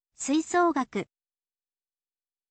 suisougaku